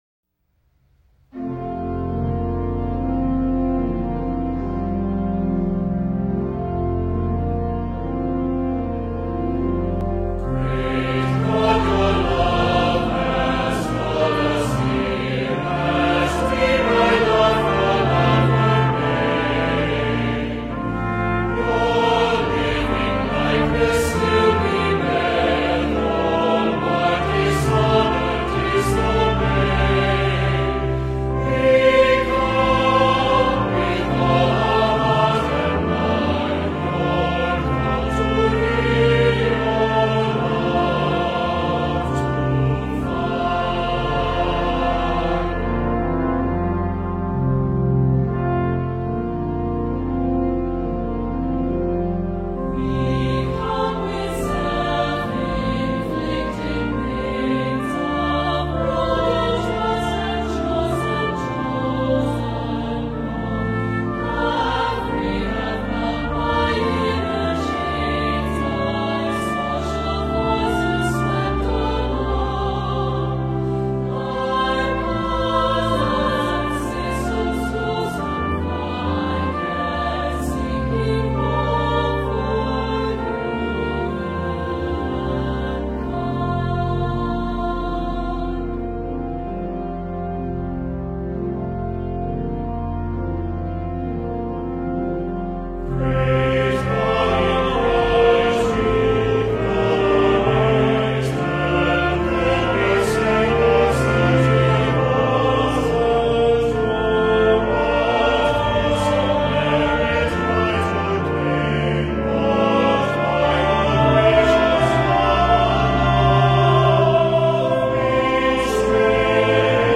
Online Worship and Music Bulletin
OPENING HYMN Great God, Your Love Has Called Us Here                   Music: Michael Joncas (b. 1951)